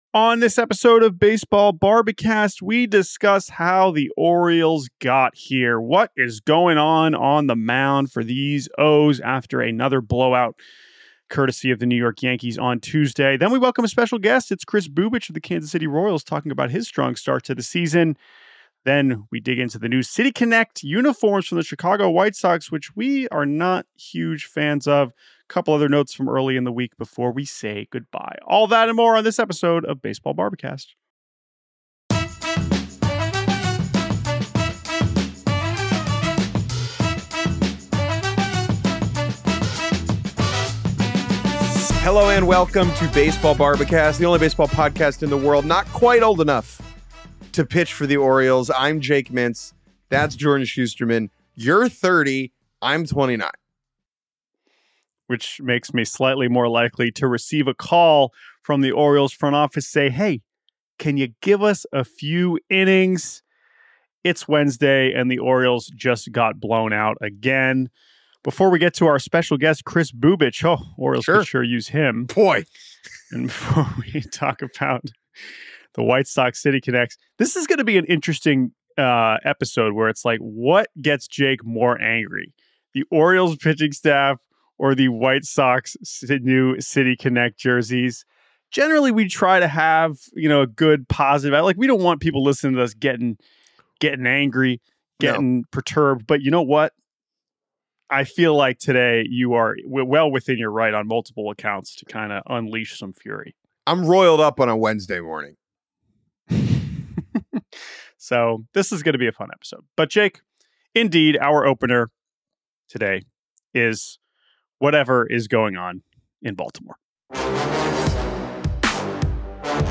Kris Bubic interview